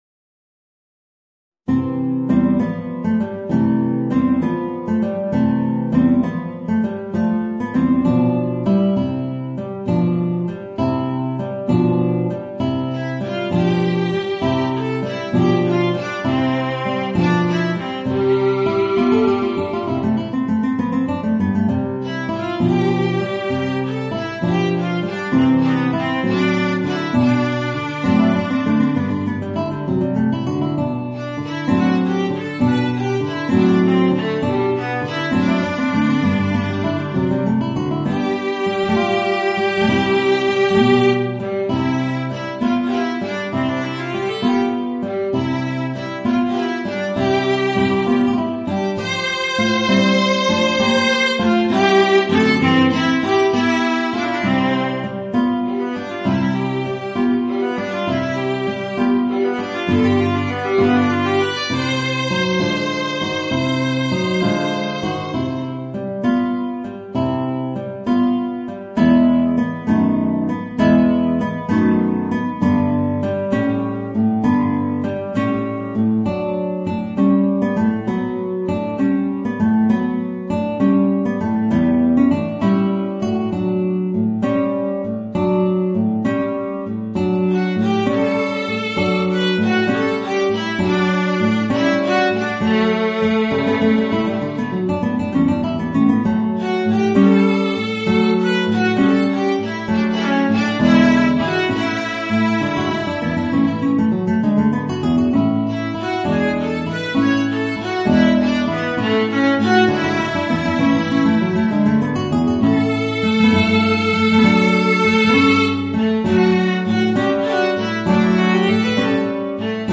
Voicing: Viola and Guitar